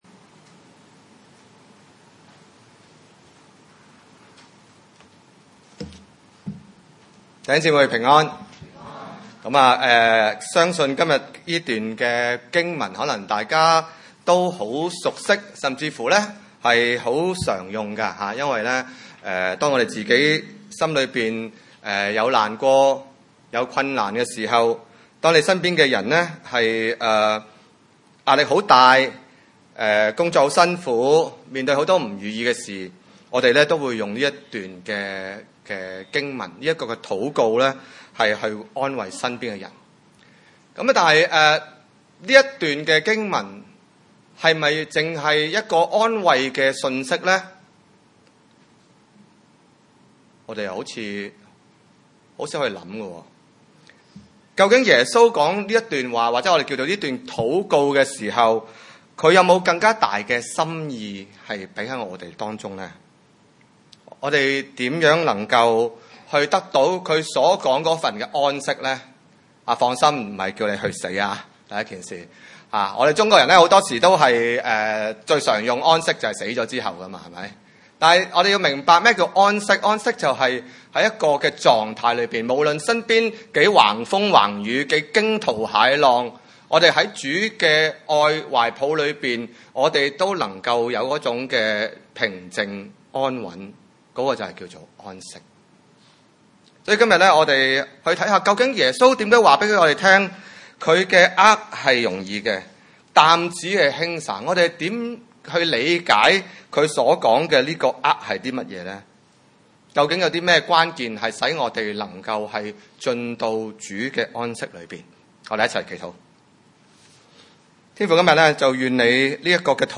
馬太福音 11:25-30 崇拜類別: 主日午堂崇拜 25 那時，耶穌說：「父啊，天地的主，我感謝你！